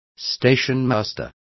Complete with pronunciation of the translation of stationmasters.